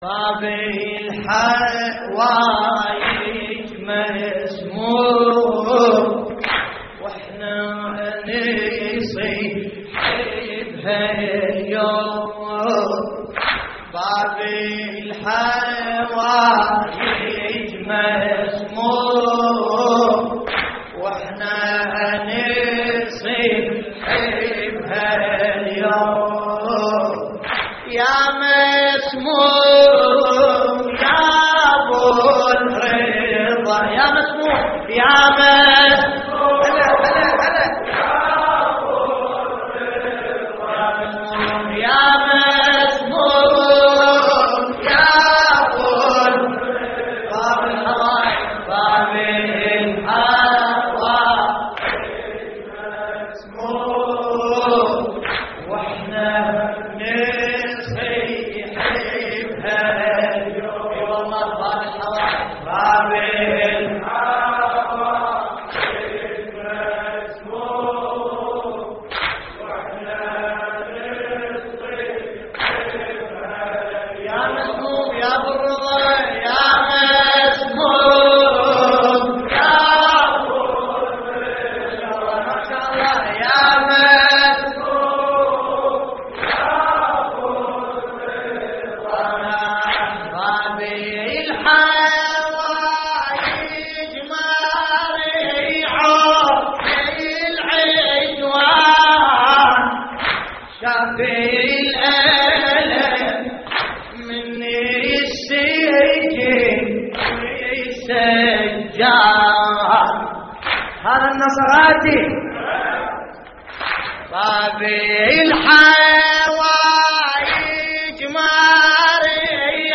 موقع يا حسين : اللطميات الحسينية باب الحوايج مسموم واحنا نصيح بهاليوم - 25رجب 1421هـ لحفظ الملف في مجلد خاص اضغط بالزر الأيمن هنا ثم اختر (حفظ الهدف باسم - Save Target As) واختر المكان المناسب